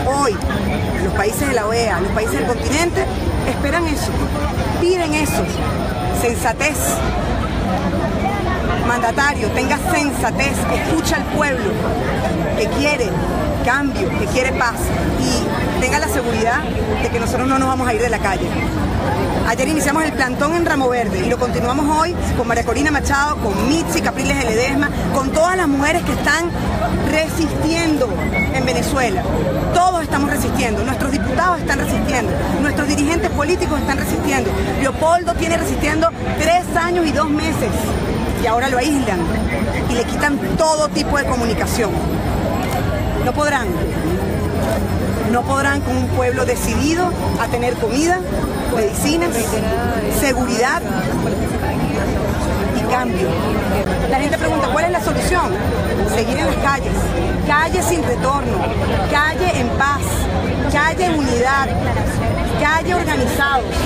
Declaraciones de Lilian Tintori